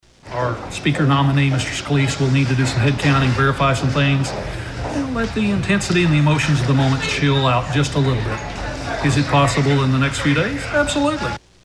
CLICK HERE to listen to commentary from Congressman Frank Lucas.